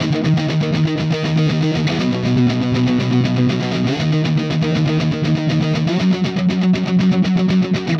Power Pop Punk Guitar 02b.wav